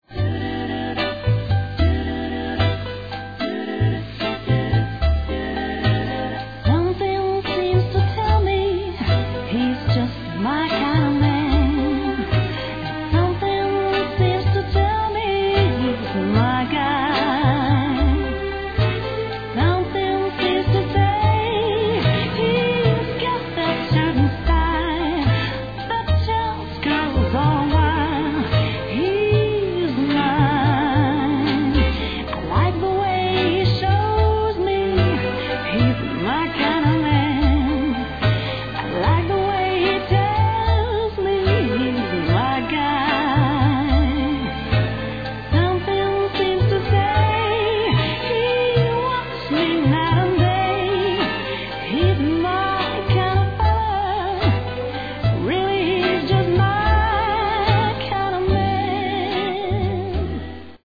Die traumhafte  Ballade aus der Werbung